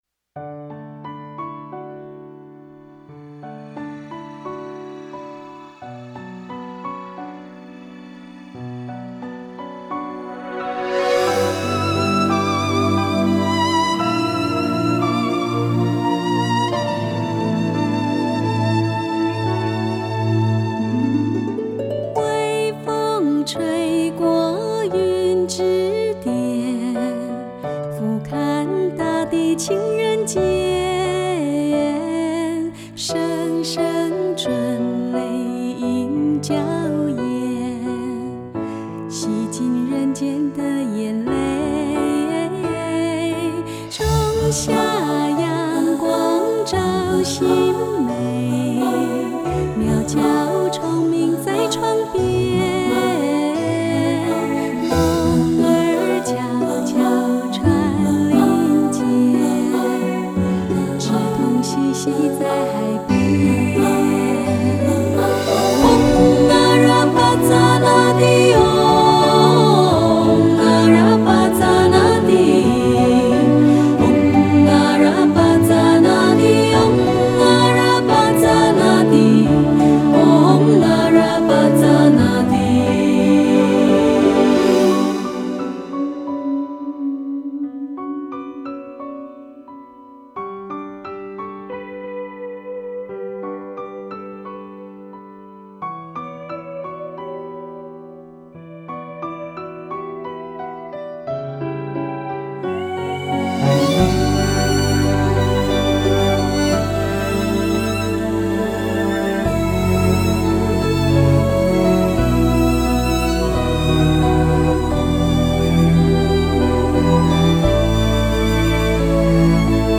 合音